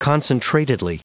Prononciation du mot concentratedly en anglais (fichier audio)
Prononciation du mot : concentratedly